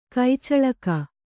♪ kai caḷaka